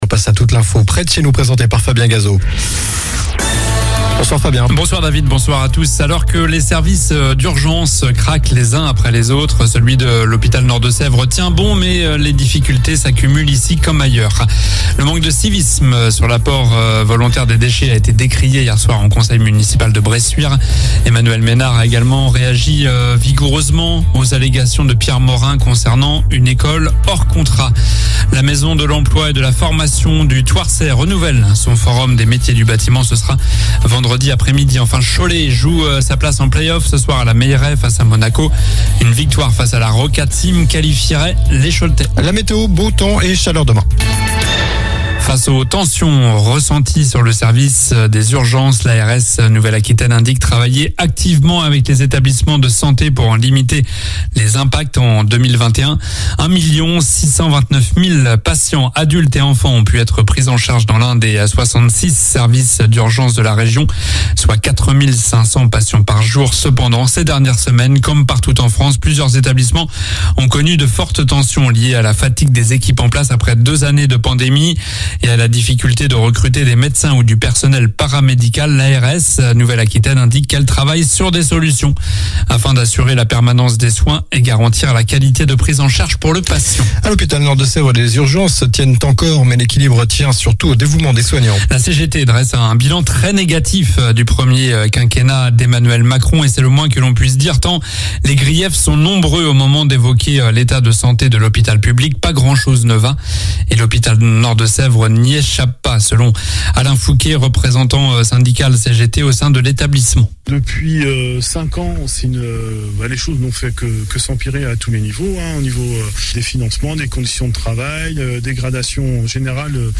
Journal du mardi 17 mai (soir)